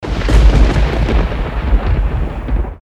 • Качество: 320, Stereo
звуки природы
гроза